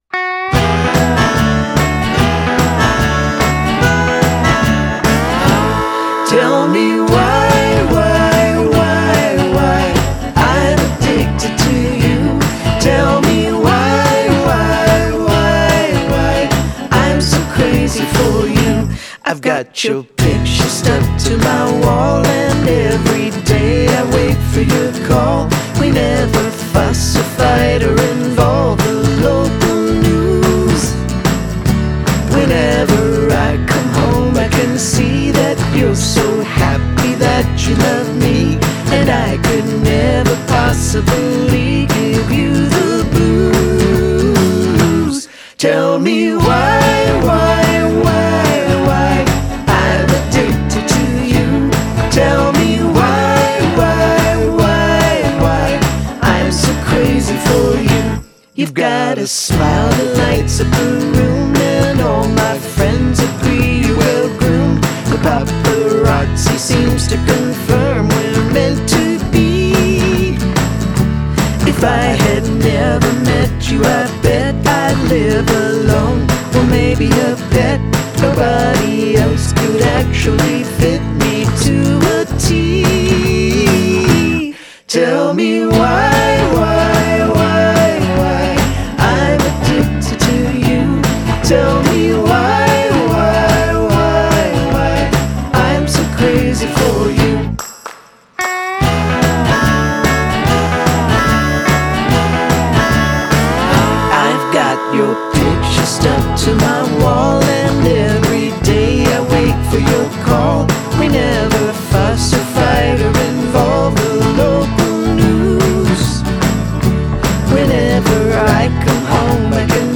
features a great chorus